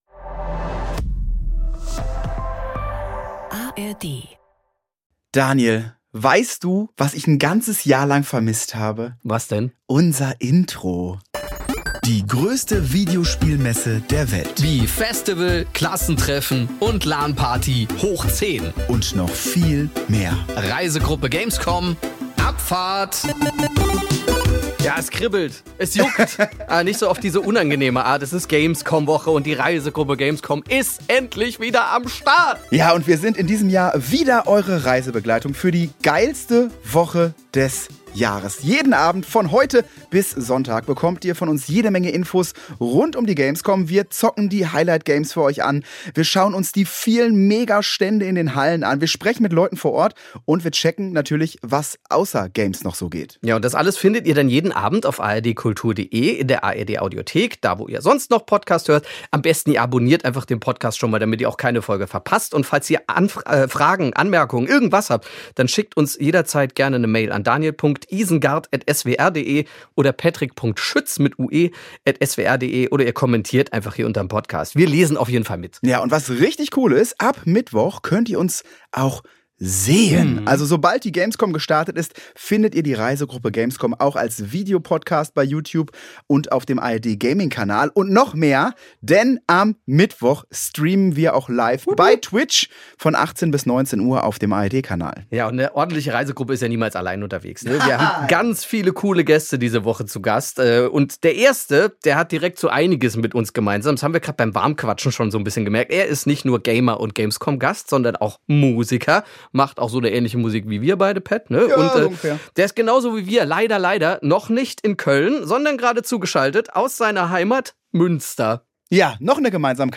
Die ganze Woche lang begleiten die beiden Hosts den schönsten Zockertreff des Jahres und ihr seid mit diesem Podcast mit dabei! Zum Warm-up holen sie sich mit INGO KNOLLMAN, dem Sänger der legendären Ibbenbürener Punkband DONOTS, einen Gaming-erfahrenen Solo-Play-Enthusiasten mit an Bord. Gemeinsam suchen die drei nach der Faszination von Videospielen, ergründen Ingos persönliche Spiele-History und fragen sich zusammen, in welchem der beiden Knollmannschen Kinderzimmer wohl die Switch verschwunden ist...